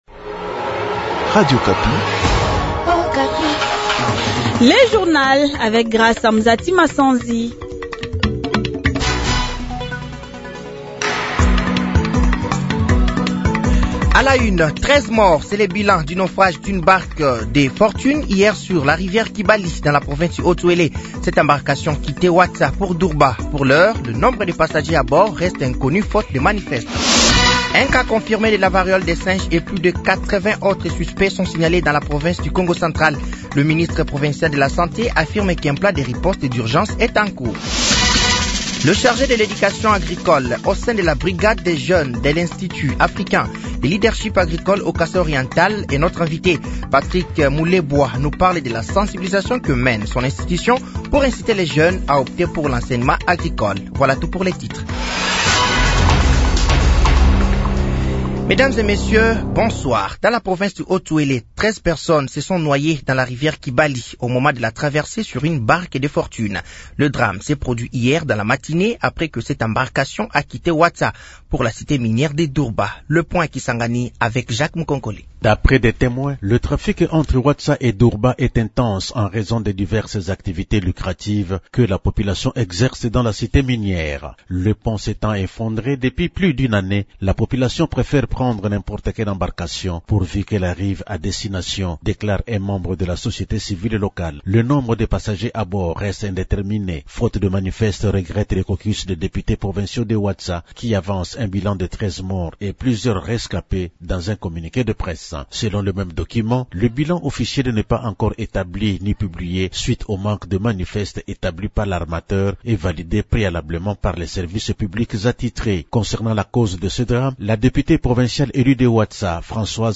Journal français de 18h de ce dimanche 08 septembre 2024